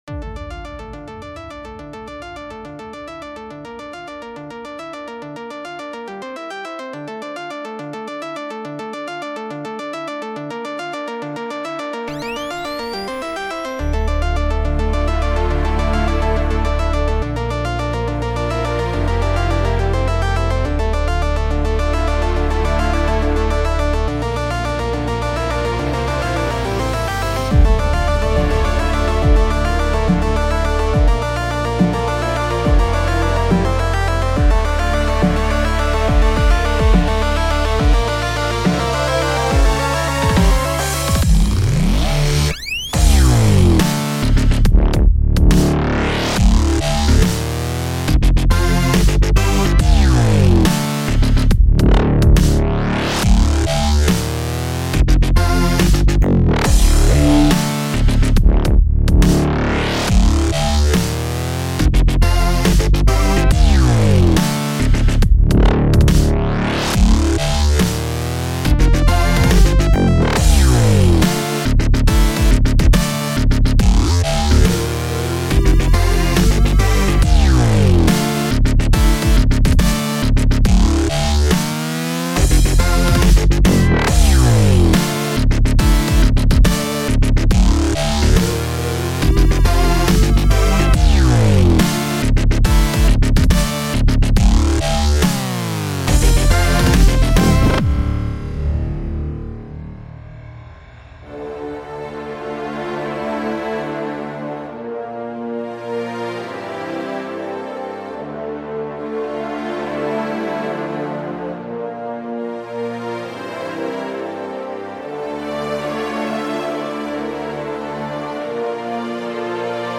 Apparently that added ~15 seconds of silence to the end.)
I do have another electronic piece coming up though.